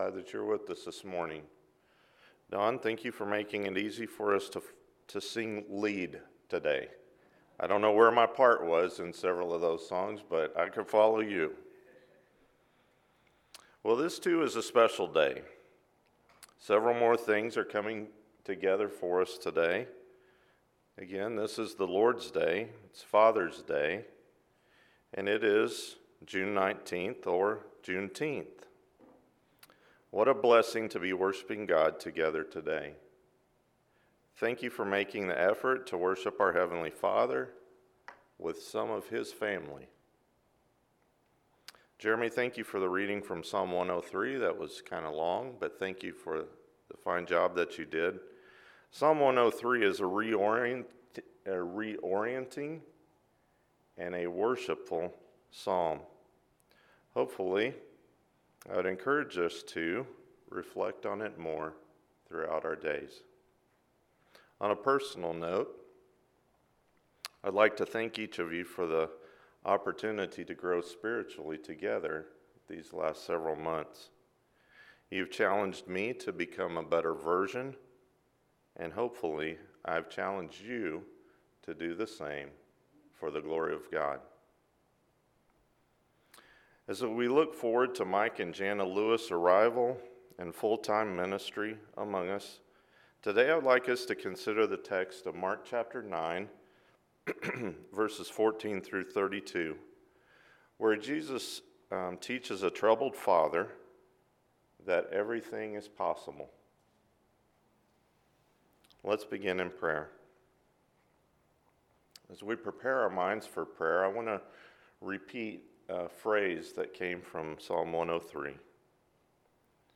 Everything is Possible – Mark 9:14-32 – Sermon